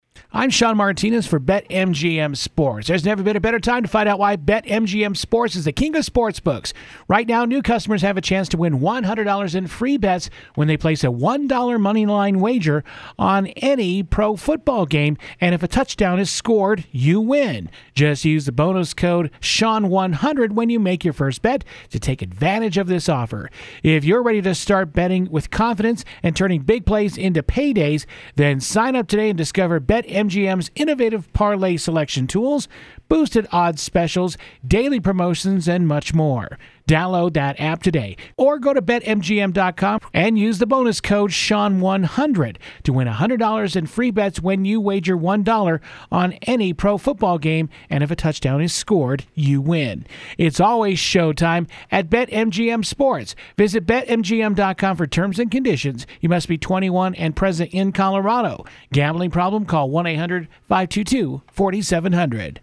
Voice Sample 1: